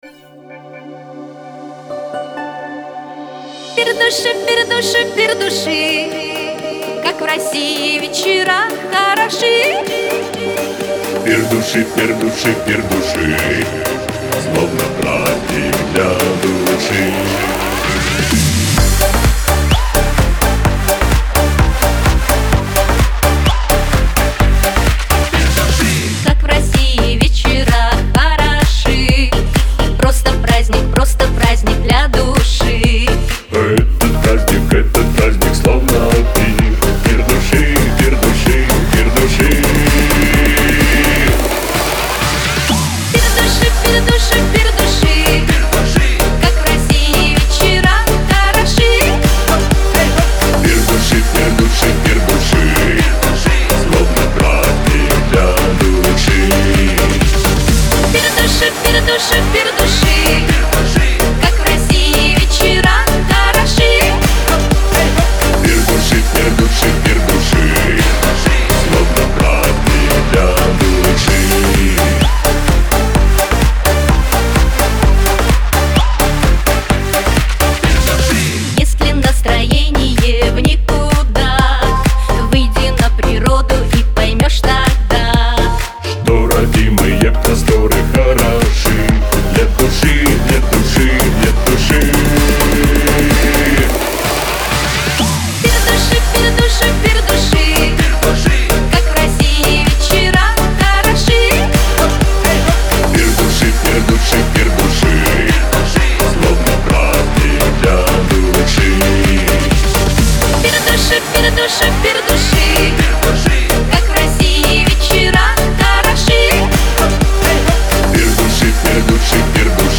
эстрада
диско
танцевальная музыка , Веселая музыка , pop